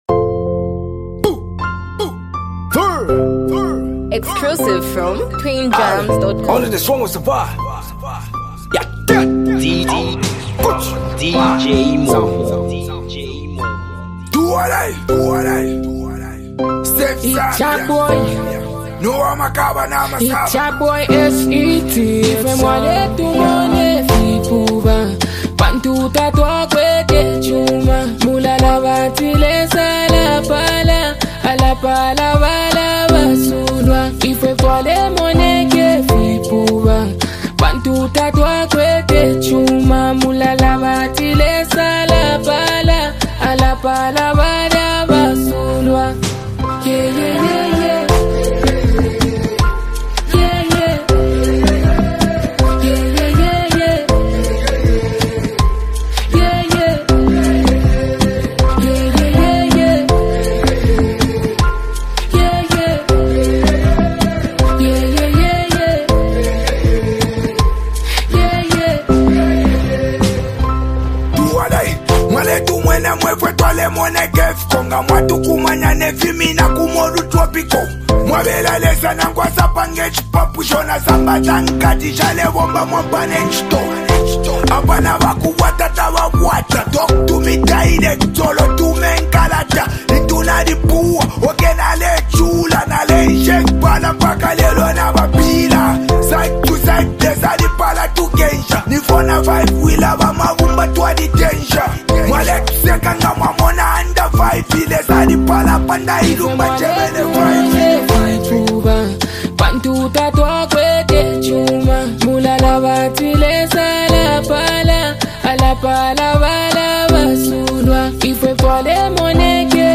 soulful vocals
harmonies and rhythmic interludes